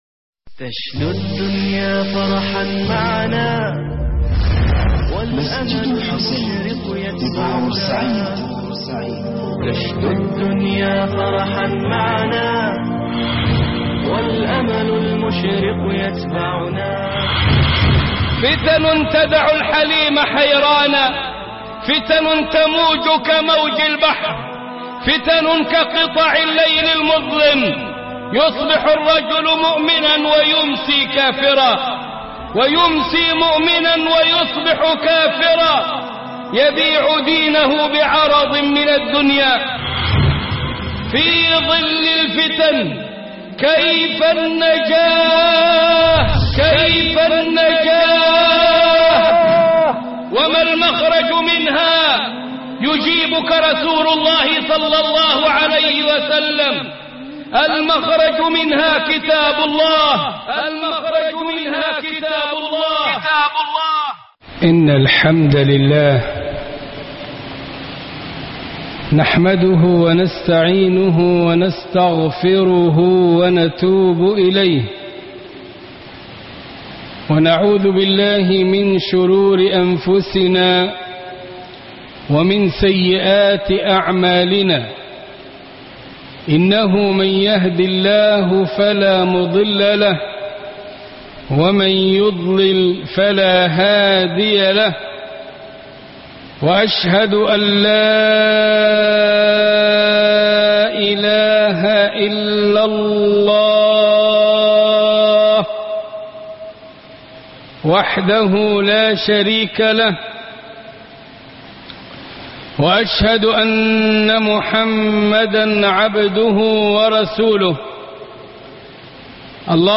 خطب الجمعة